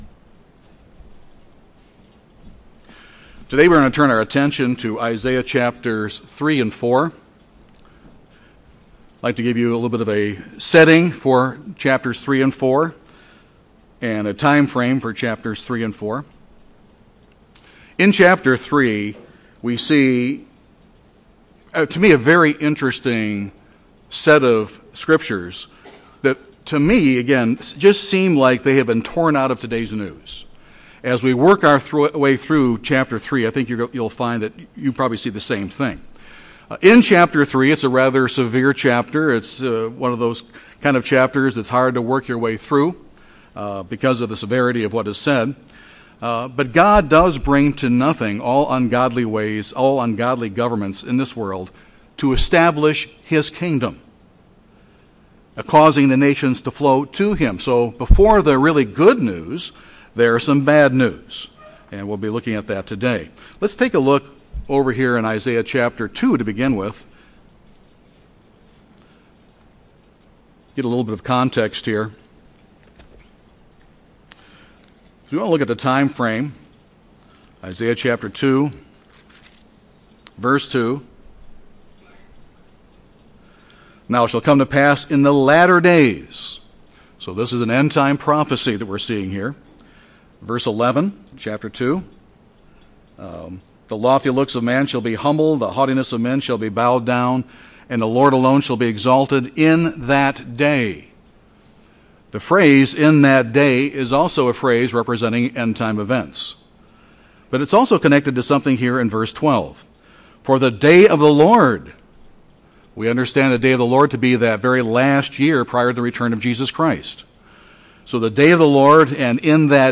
This sermon continues the Isaiah series with Chapters 3 and 4.